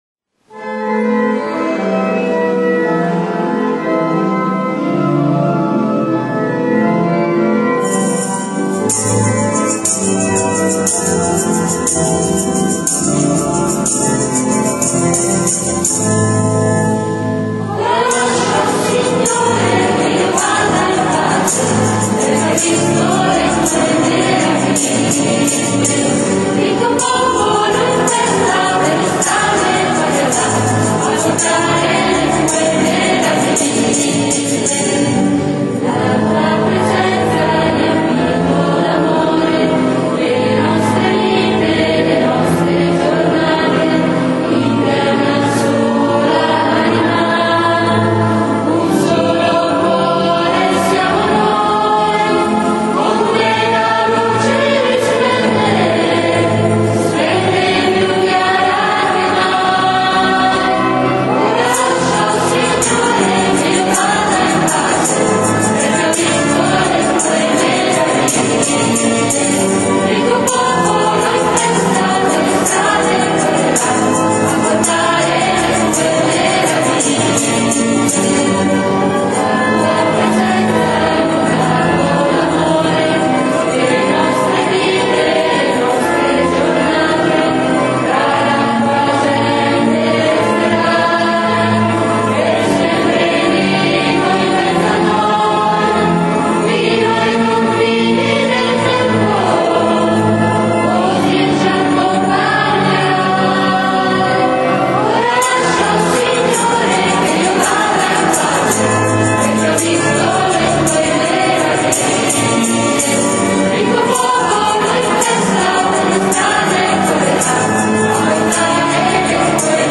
PASQUA DI RESURREZIONE
canti: